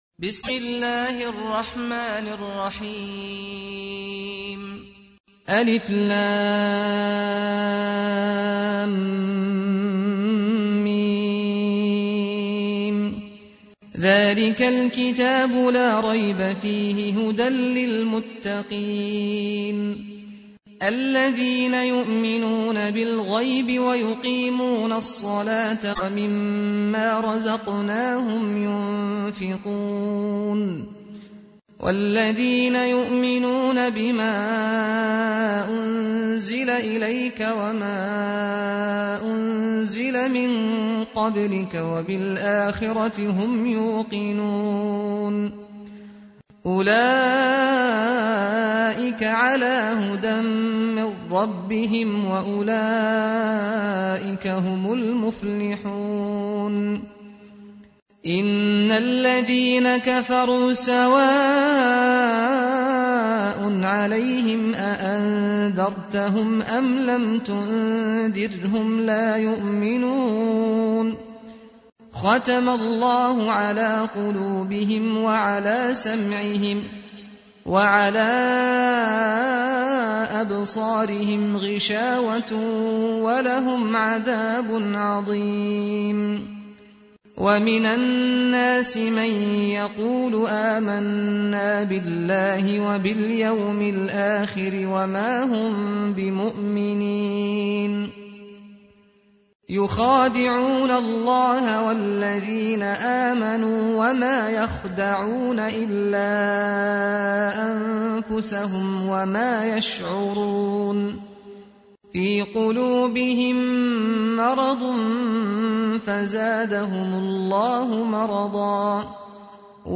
تحميل : 2. سورة البقرة / القارئ سعد الغامدي / القرآن الكريم / موقع يا حسين